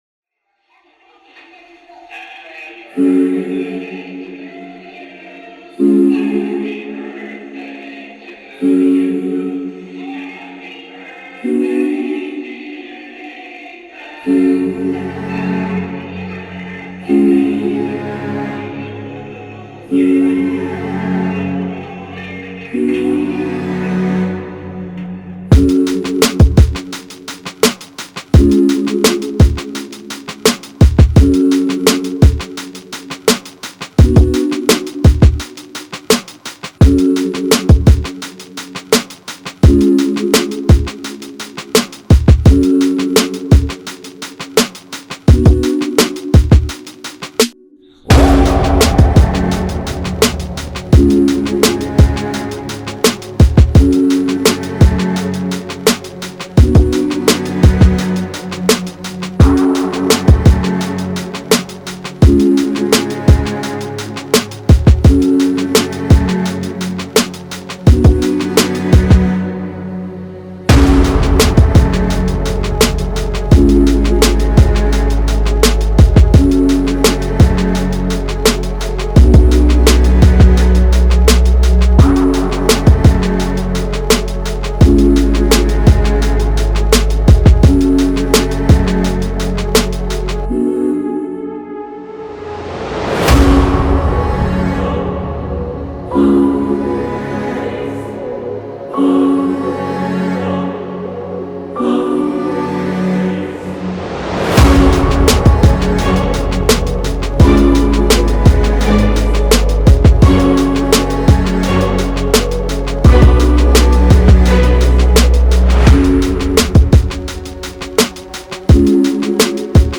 Rap Instrumental